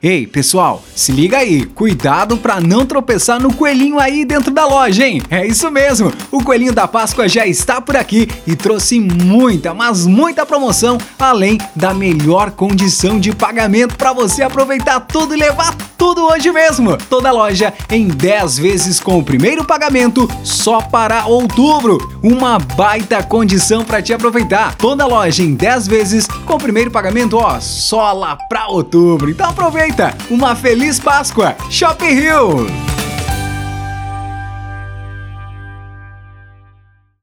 Natural: